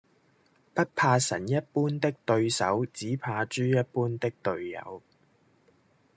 Голоса - Гонконгский 126